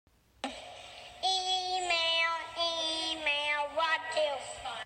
Email Alert Zil sesi
cool , simple , beep , email , tome ,